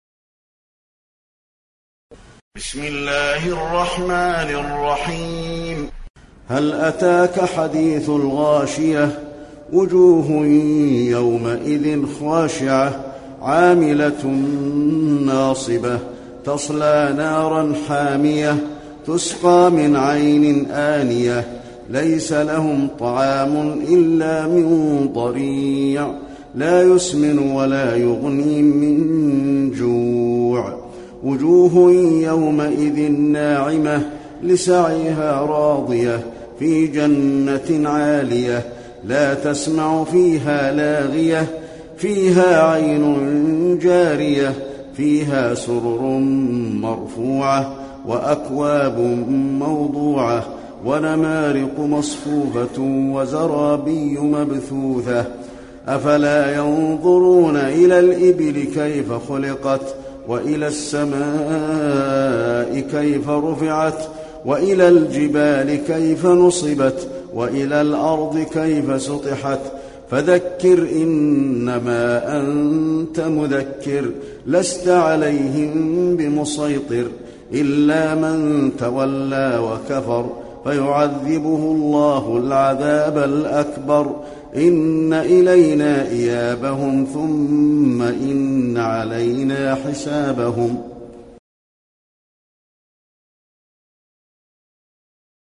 المكان: المسجد النبوي الغاشية